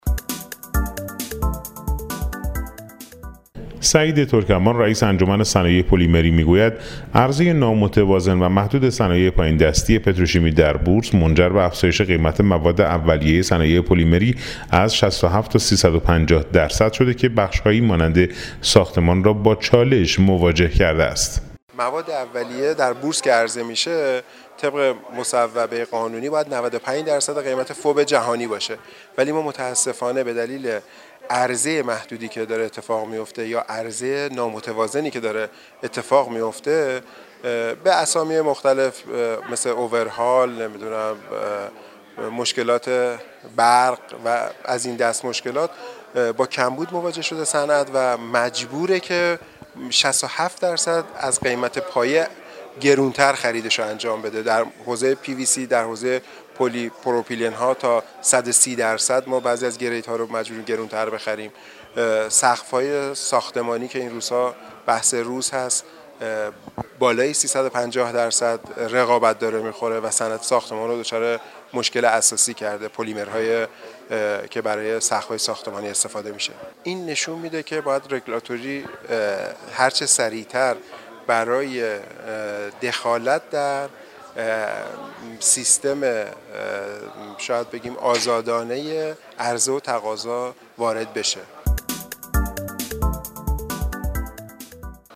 جهش قیمت مواد اولیه پلیمری با عرضه محدود صنایع پایین دستی پتروشیمی در بورس / گزارش رادیویی